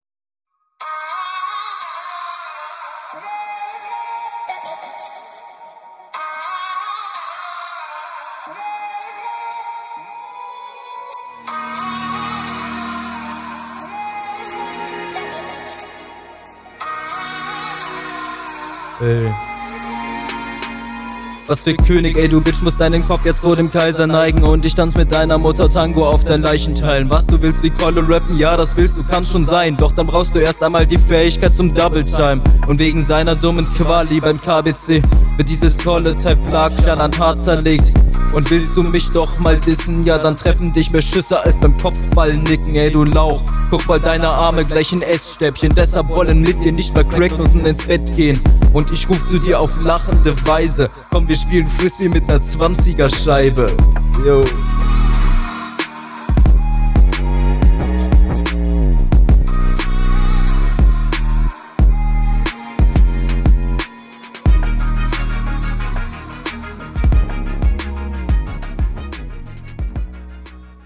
Die erste Line fand ich inhaltlich eigentlich ganz cool, die Delivery war halt bisschen mau.